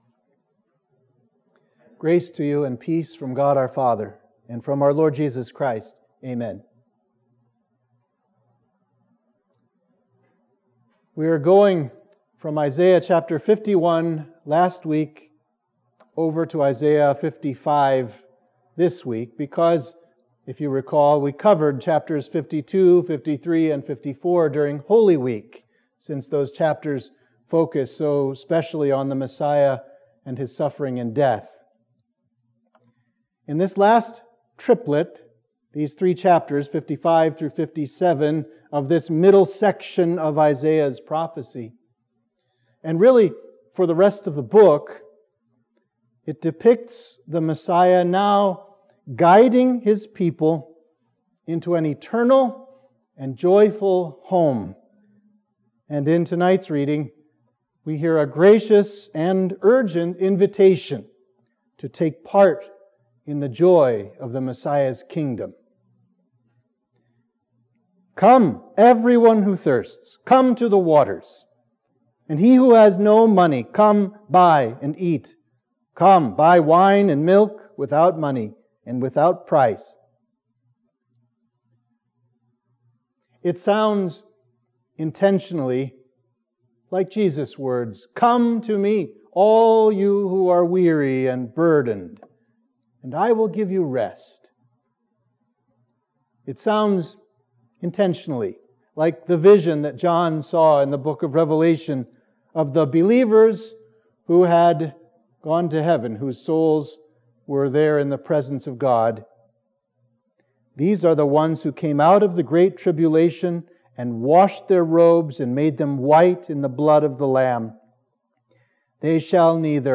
Sermon for Midweek of Trinity 11